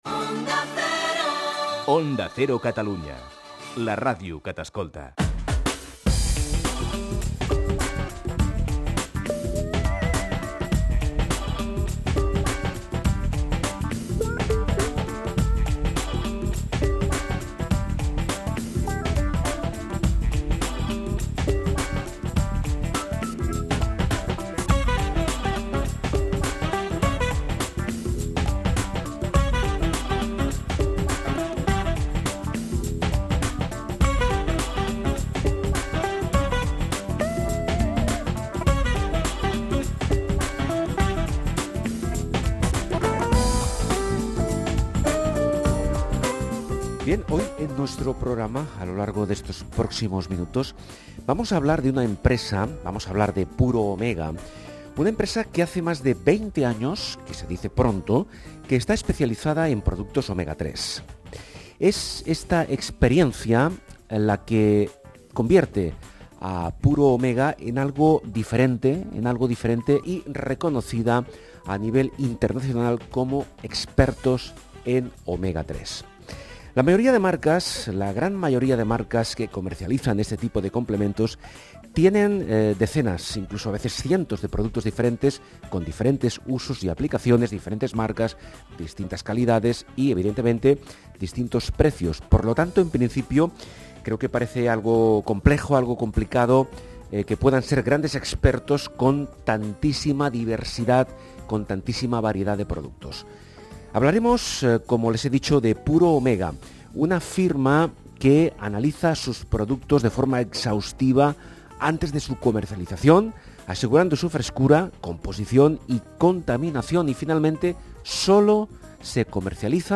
Entrevista en Onda Cero